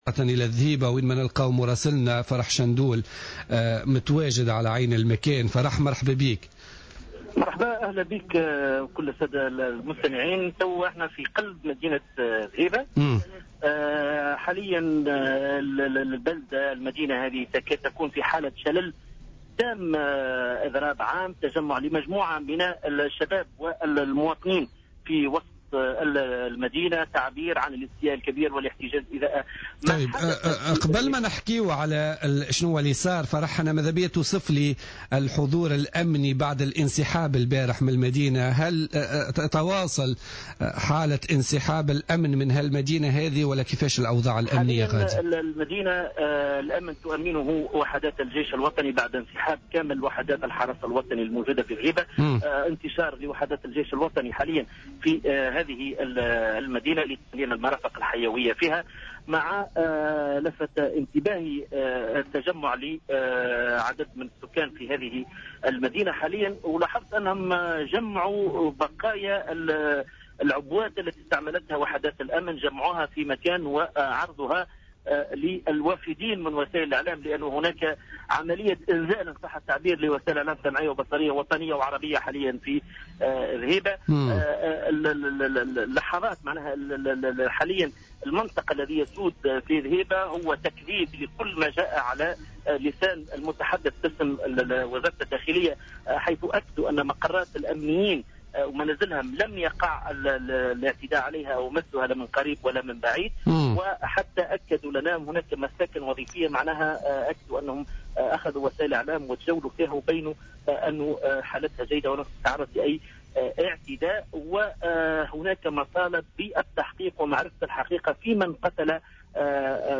Le dirigeant de Nidaa Tounes, Abdelaziz Kotti, a déclaré lundi lors de son passage sur le plateau de Jawhara Fm que le droit de manifester est une ligne rouge à ne pas franchir disant que tous les Tunisiens ont le droit de descendre dans la rue pour s’exprimer.